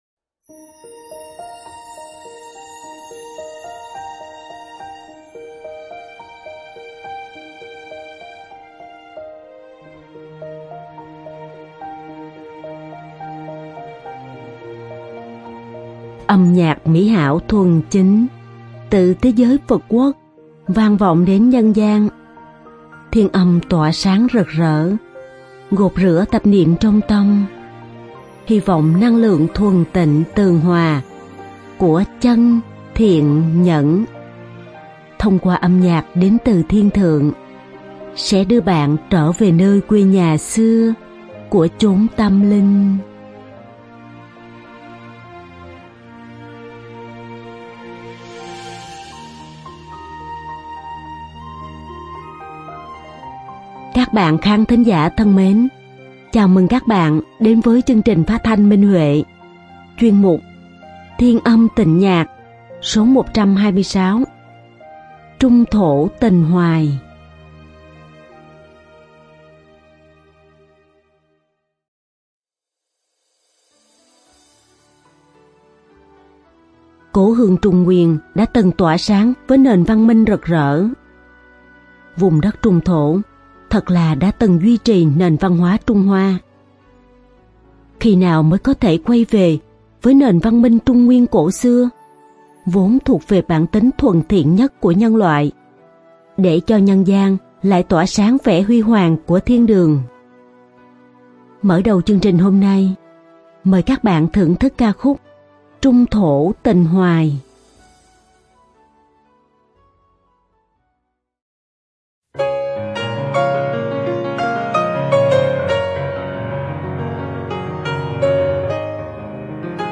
Đơn ca nữ